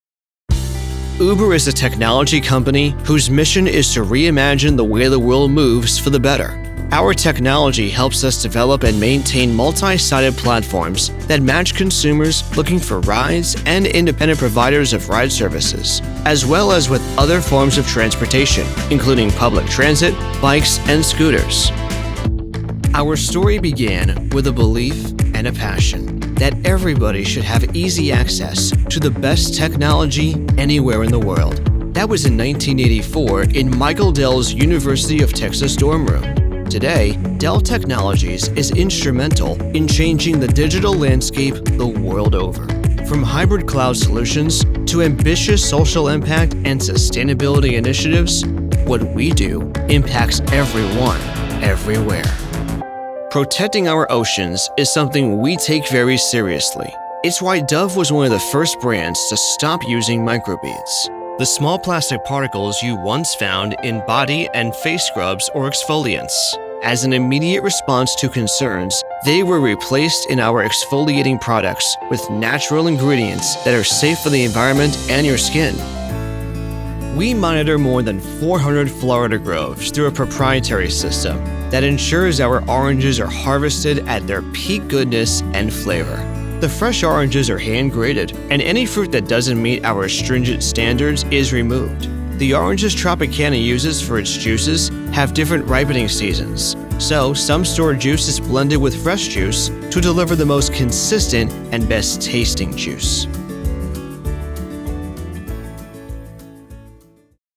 Corporate Narration Demo
English-North American, English-Neutral
Young Adult